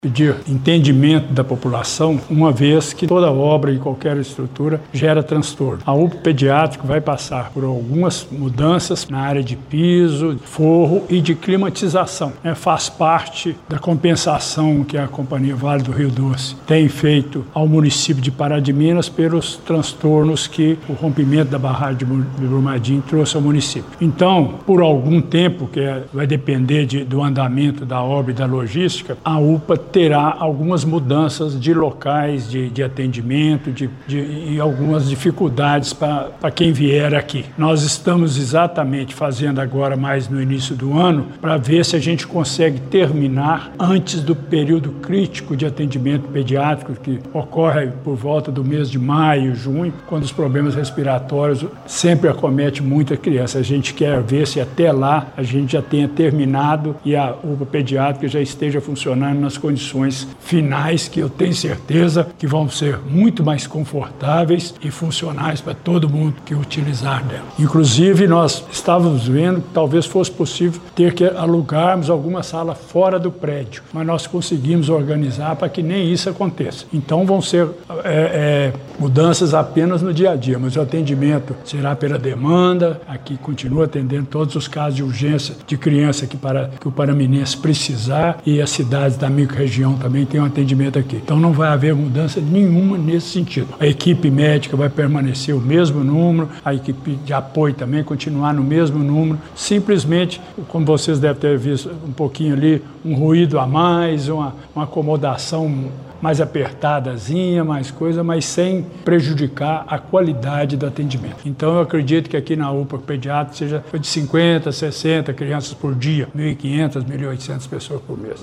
Durante coletiva de imprensa realizada nesta quinta-feira, 29, na UPA Pediátrica de Pará de Minas, o secretário municipal de Saúde, Gilberto Denoziro Valadares da Silva, anunciou alterações pontuais na logística de atendimento da unidade.